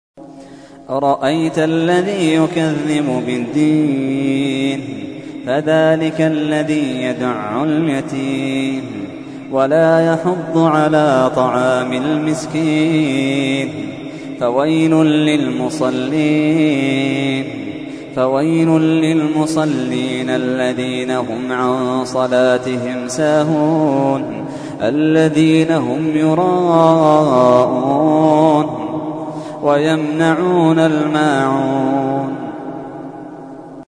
تحميل : 107. سورة الماعون / القارئ محمد اللحيدان / القرآن الكريم / موقع يا حسين